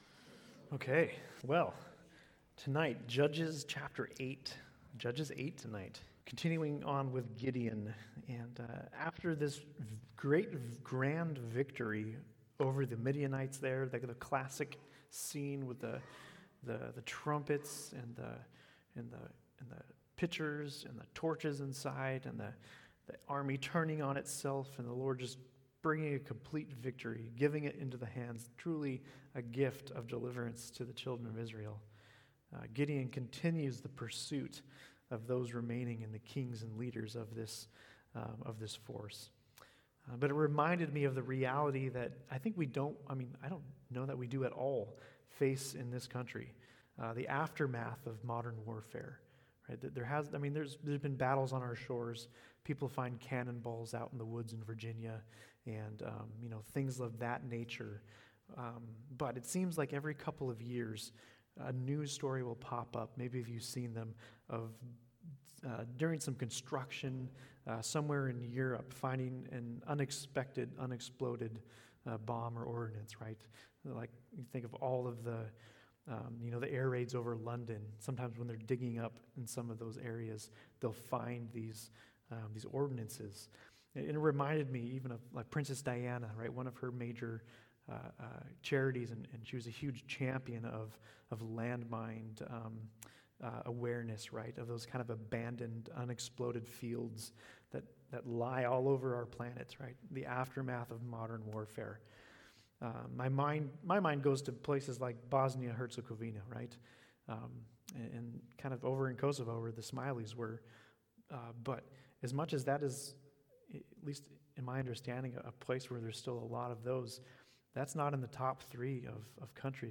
Sermons - Calvary Chapel Eureka